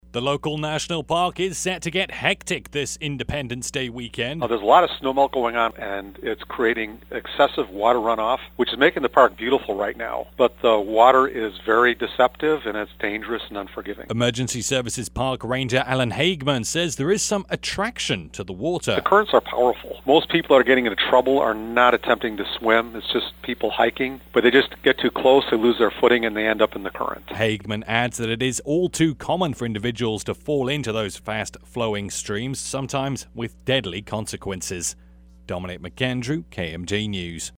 as it aired: